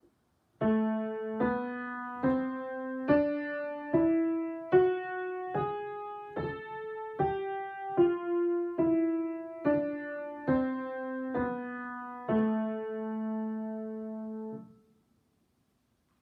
上りと下りで音階に変化が！旋律的短音階と自然短音階
さらに、下りでは自然短音階が活用されることで、雲が晴れるような安心の音並びに落ち着くことも印象的です。
まずは上り、全音の連続に高揚感を感じますよね。
Aminor_Melodic.m4a